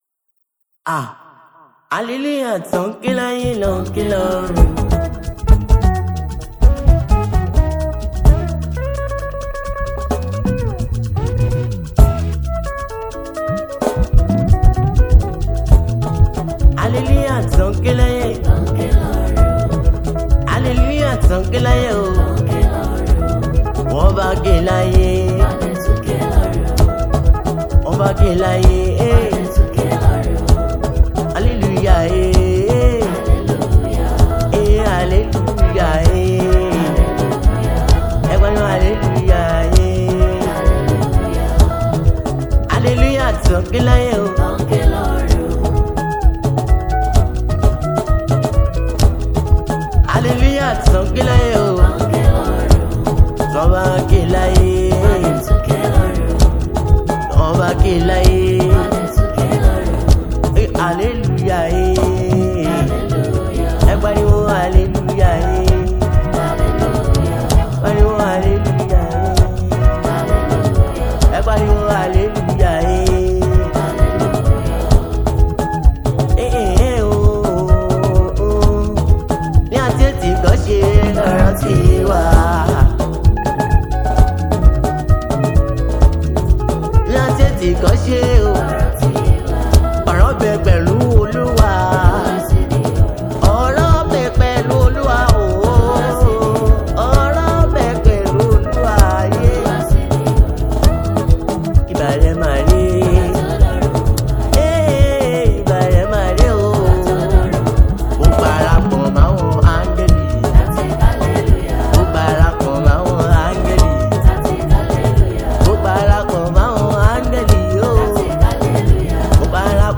Yoruba Gospel Music
a gifted gospel singer and songwriter.